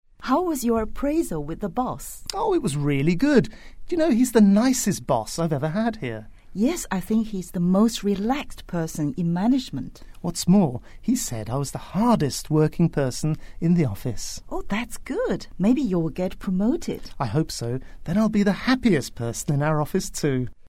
english_49_dialogue_2.mp3